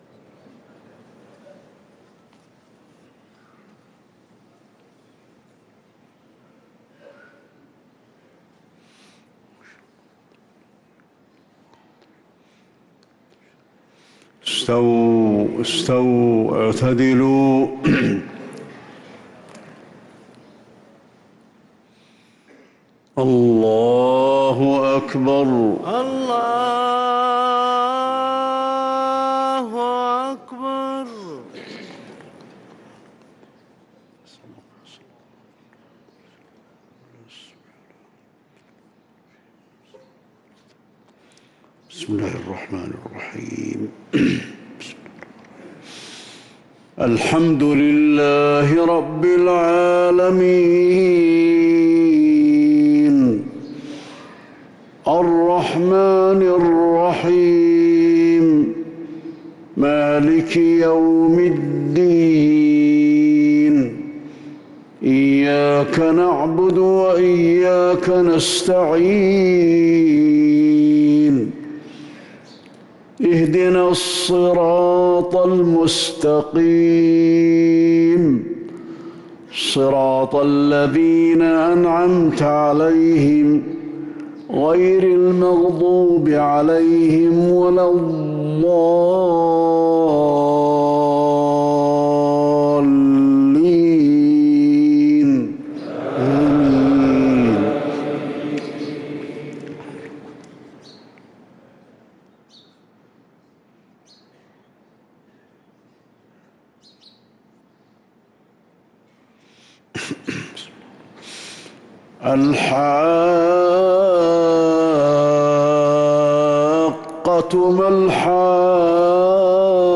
صلاة الفجر للقارئ علي الحذيفي 29 ربيع الأول 1445 هـ
تِلَاوَات الْحَرَمَيْن .